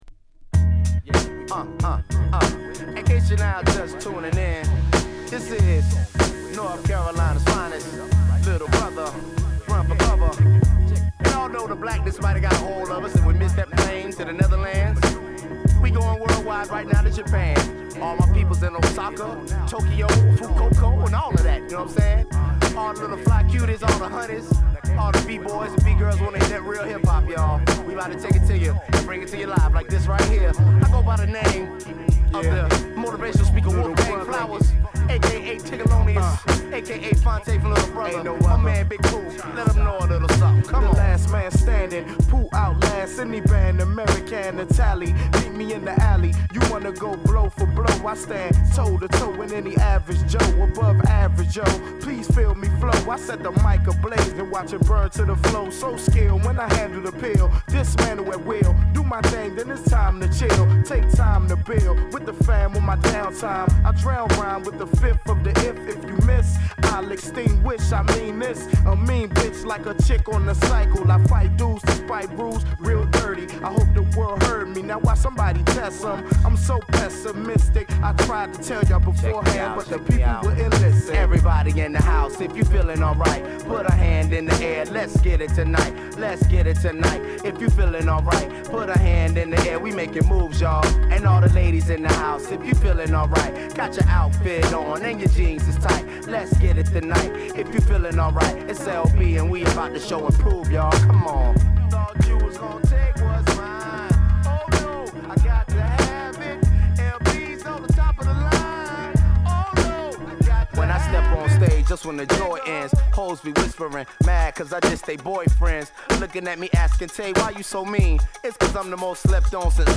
＊試聴はA1→A3→AA1です。